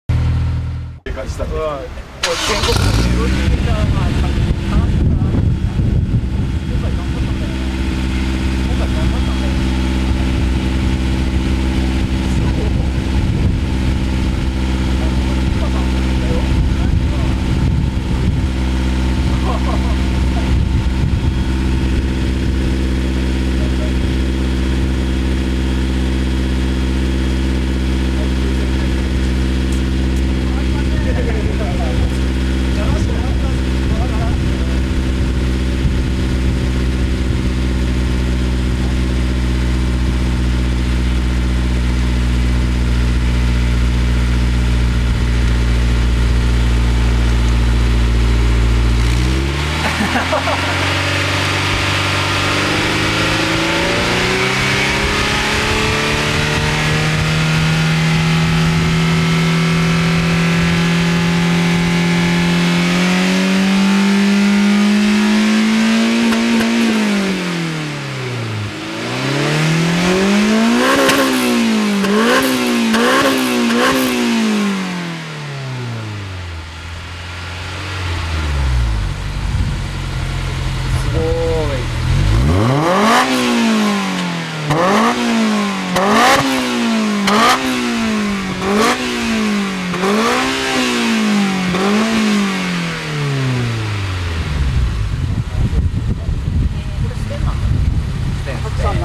(今回はS2000マフラーサウンド録音オフだよ〜）
マイク：業務用ガンマイク
『※ぴんぽ〜ん 　今回は空ぶかしメインで〜す(^◇^;)』